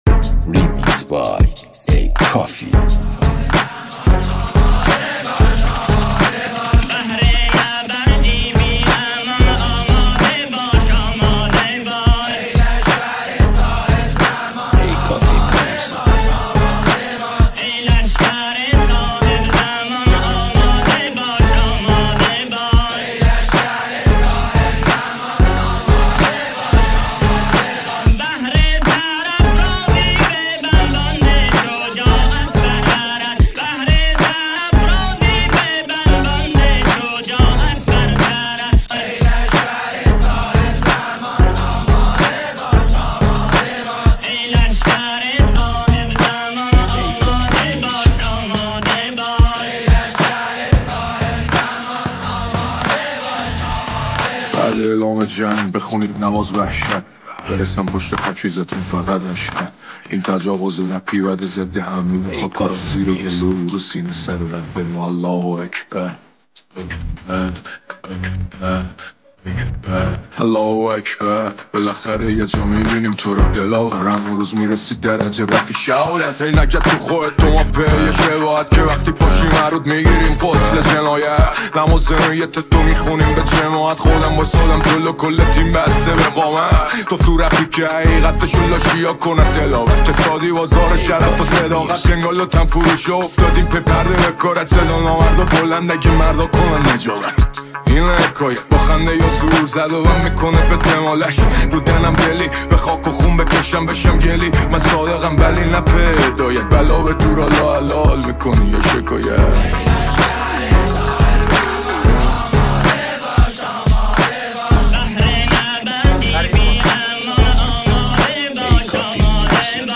• ریمیکس ~ نوحه و مداحی